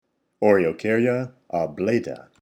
Pronunciation/Pronunciación:
O-re-o-cár-ya o-blà-ta